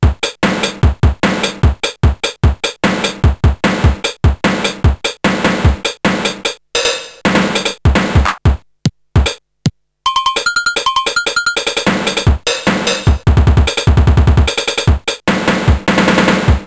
A programmable drum machine triggered by touch pads.
• 40 drum sounds (5 toms, 4 snares, 3 basses + hihats, cymbals, bongos, cowbell, clap etc.)
• 12 preset rhythms